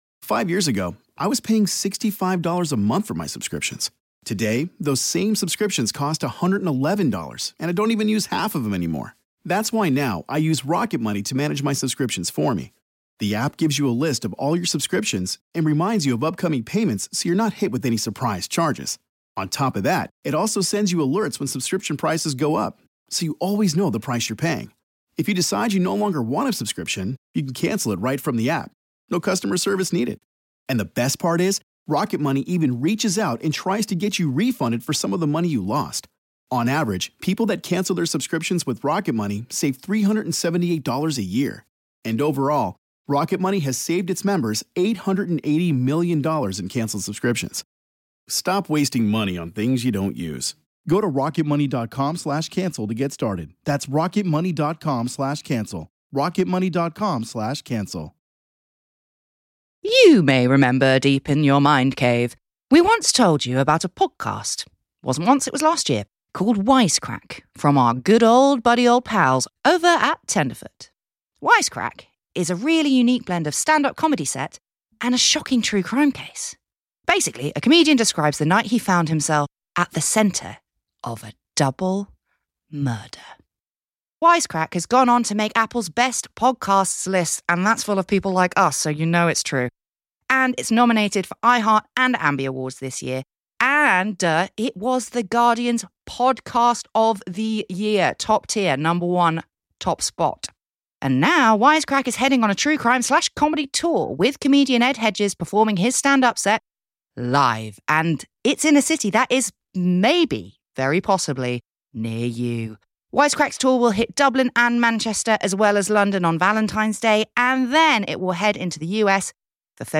This round-up includes highlights from 'In The News' our monthly show that gets stuck into the headlines of all things true crime, 'Under The Duvet' our aftershow discussion in which we catch up with one another about our lives and interesting things we've seen, and finally a segment from our latest Bonus Episode which this time involves a Nazi Cult based in Chile...